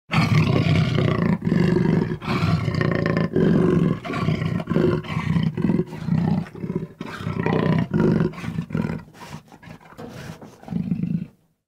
Download Jaguar sound effect for free.
Jaguar